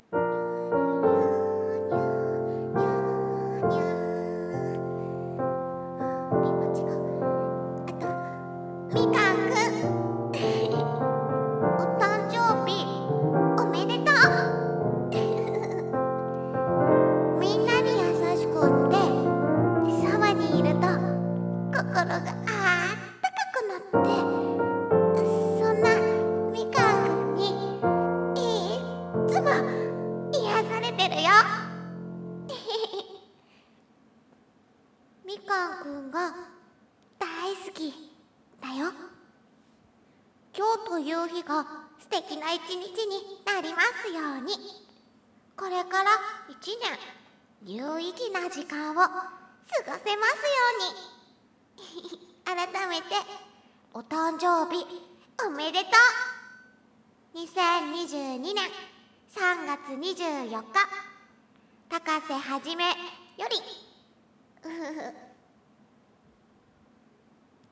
【声劇台本】🎂ハッピーバースデー🎂